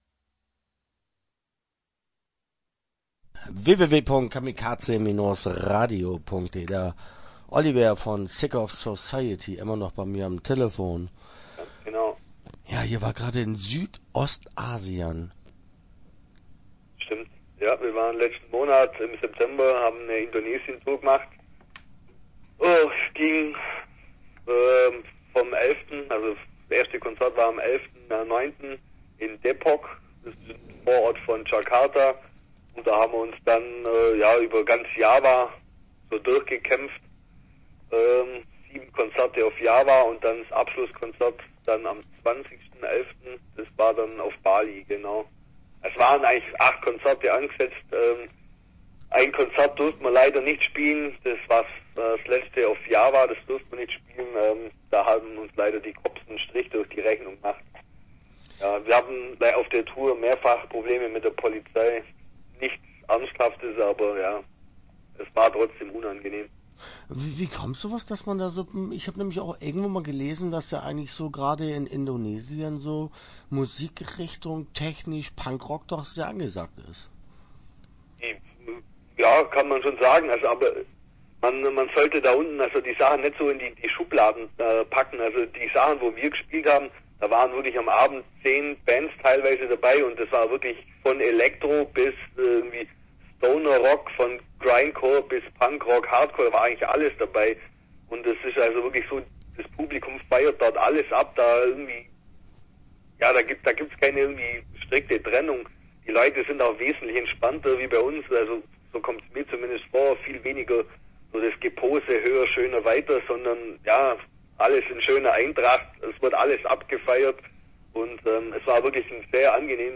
Sick of Society - Interview Teil 1 (12:18)